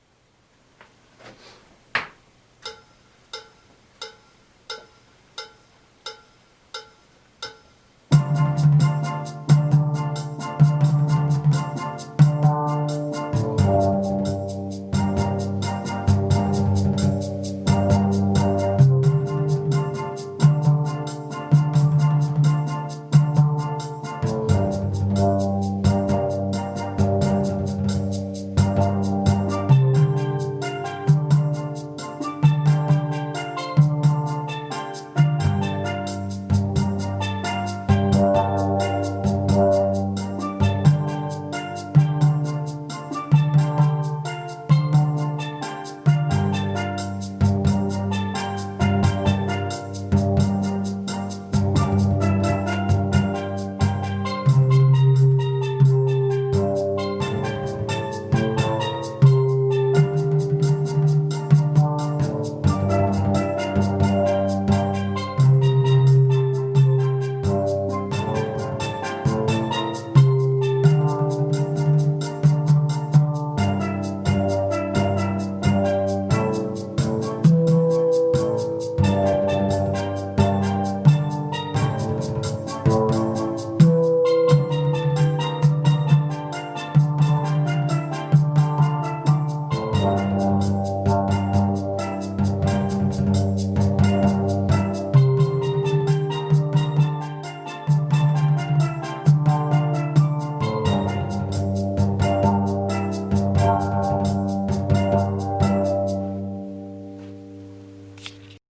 Basse Maloya Vid .mp3